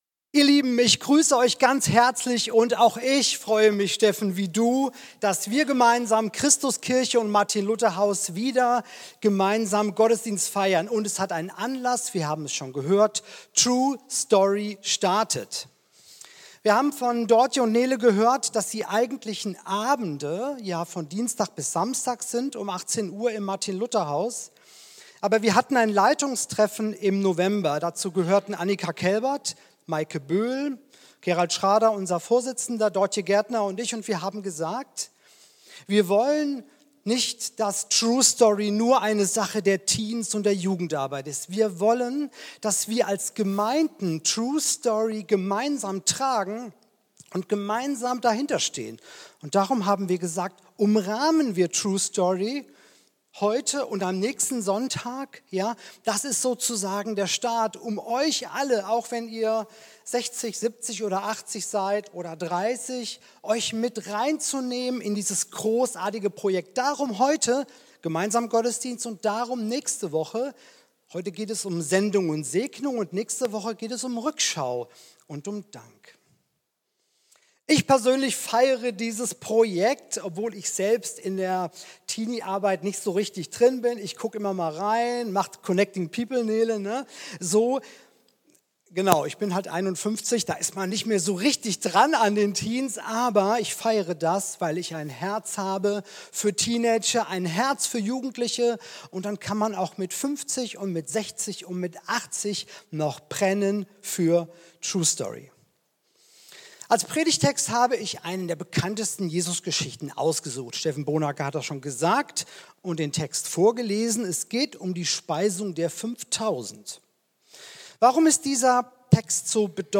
Zum Start der True-Story-Woche: Die Speisung der 5.000 ~ Christuskirche Uetersen Predigt-Podcast Podcast